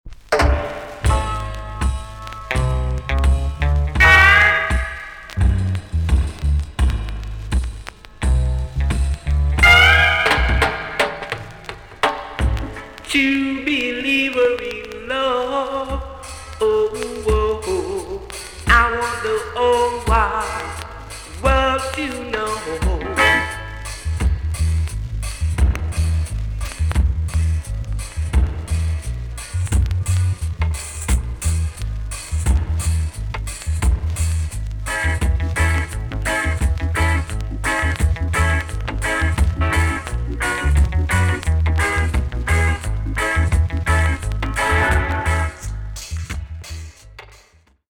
TOP >REGGAE & ROOTS
VG+ 少し軽いチリノイズがあります。